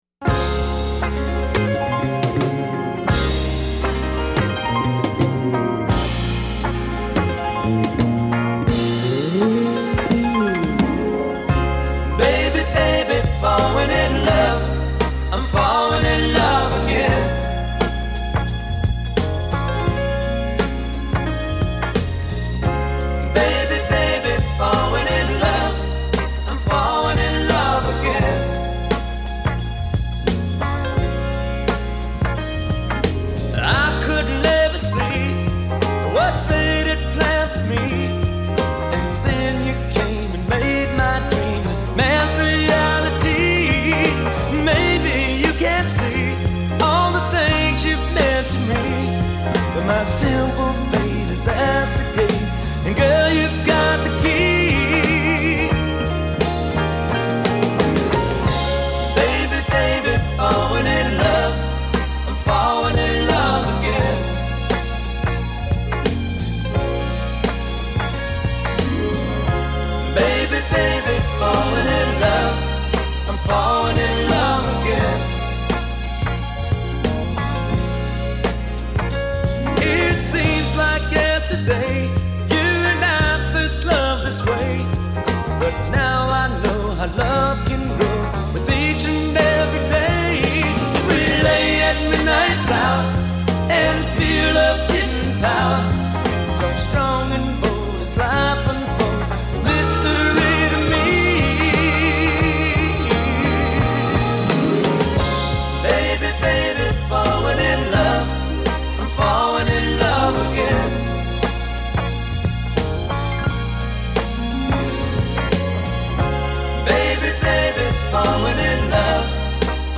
I always heard a sweet love song with beautiful harmonies